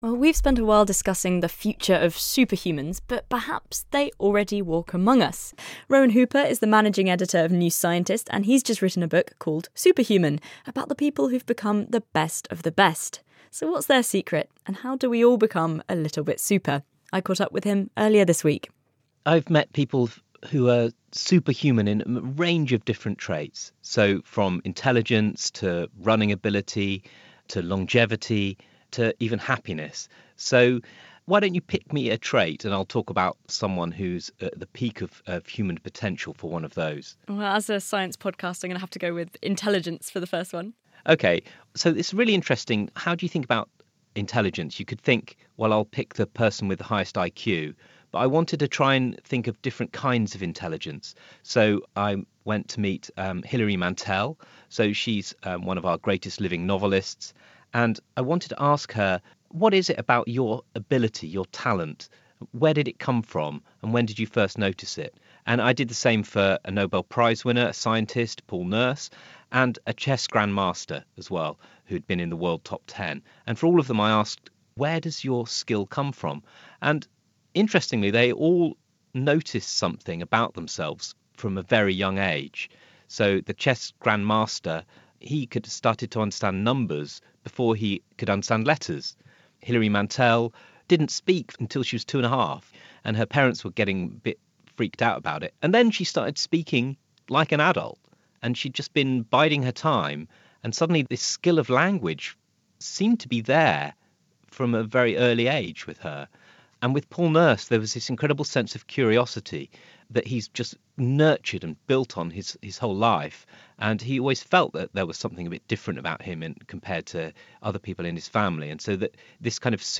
2. Interviews